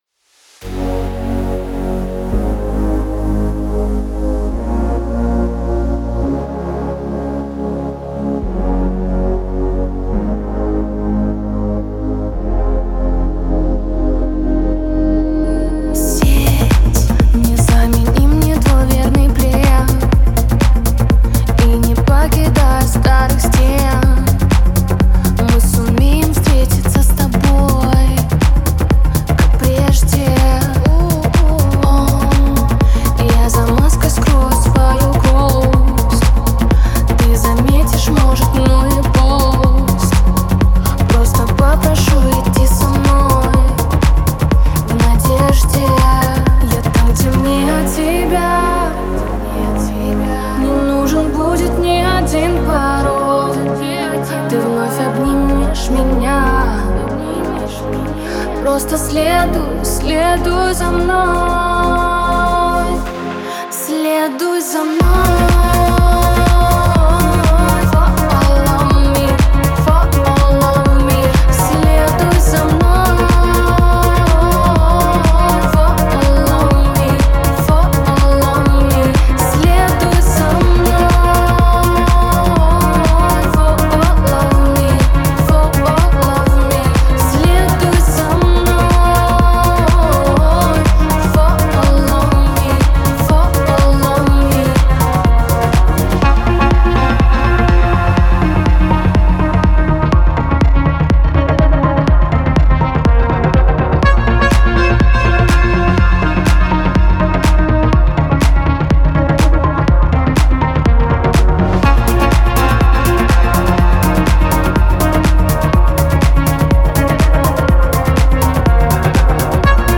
энергичная поп-песня
яркими синтезаторами и запоминающимся битом